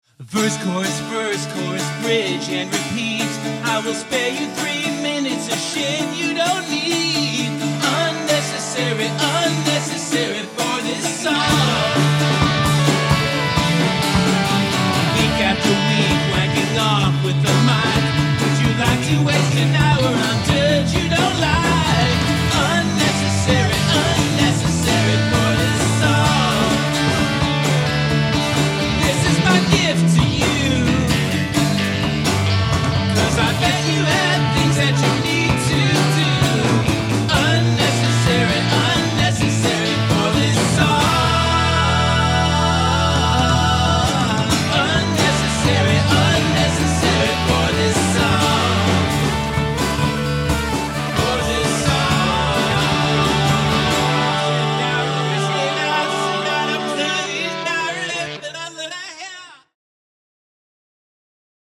Psychedelic